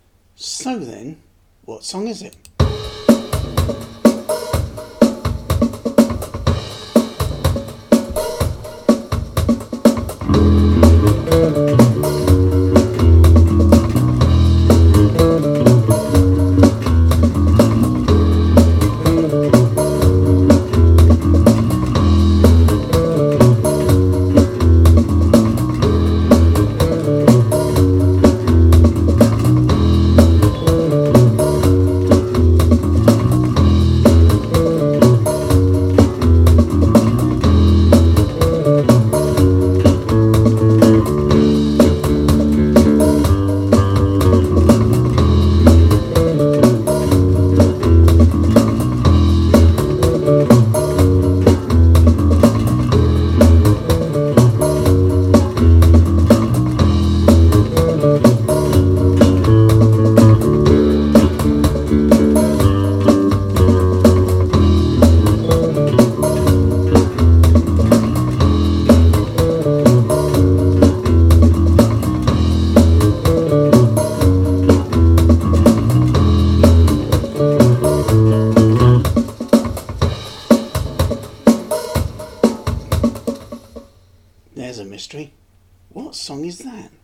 3 healing drums - with a bass - what song is this ??